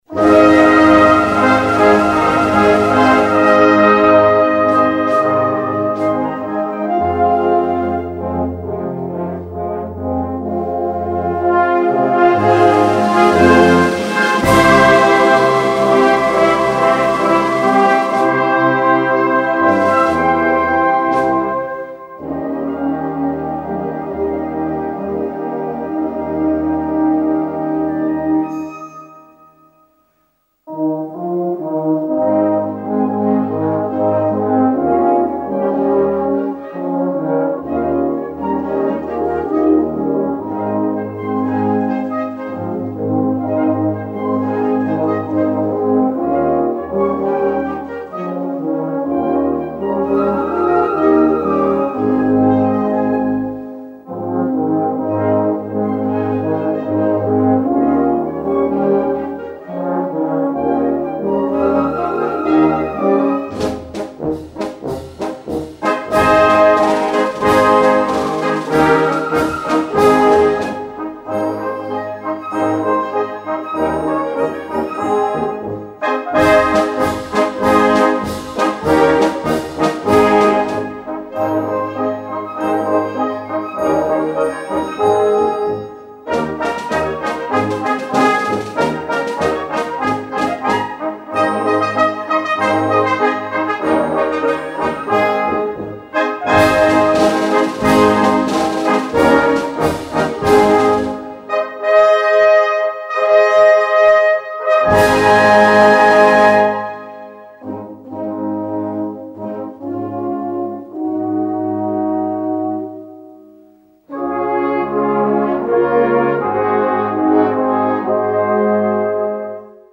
Gattung: Volkslieder-Potpourri
Besetzung: Blasorchester